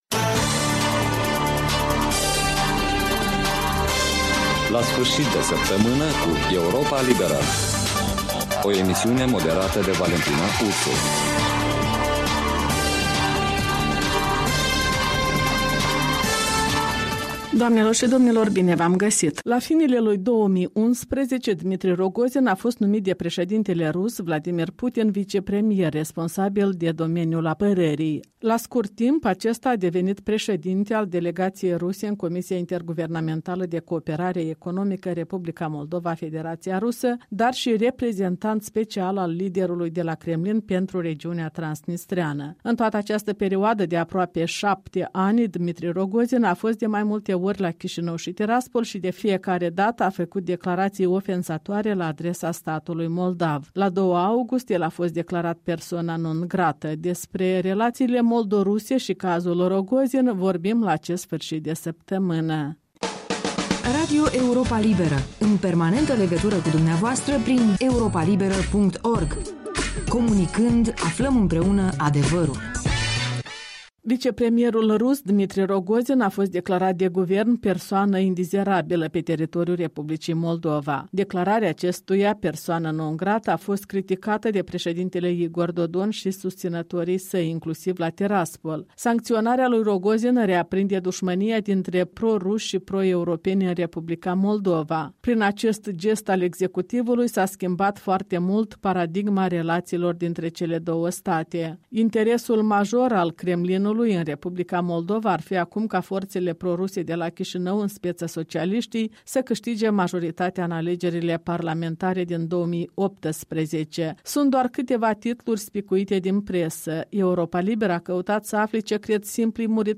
în dialolg cu locuitori ai Chișinăului